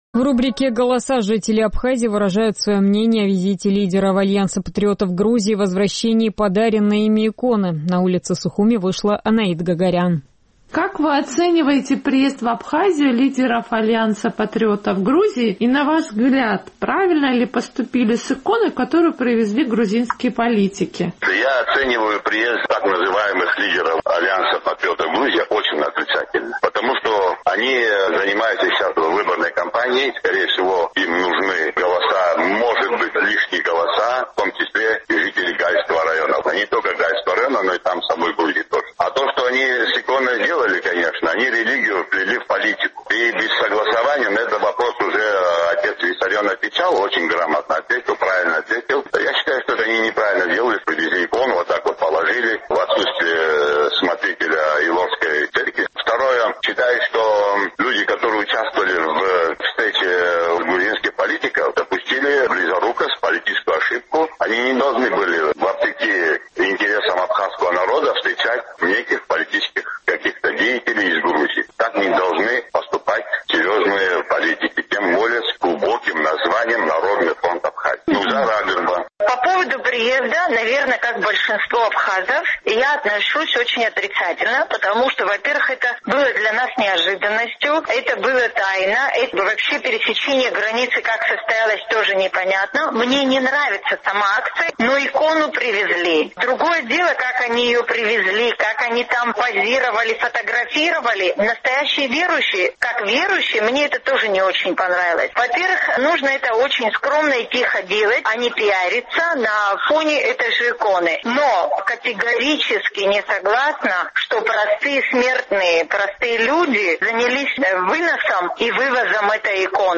В нашем традиционном опросе жители Абхазии выражают свое мнение о визите лидеров «Альянса патриотов Грузии» и возвращении подаренной ими иконы. Отношение к «Патриотам» примерно одинаковое, а вот по поводу иконы – есть и альтернативные мнения.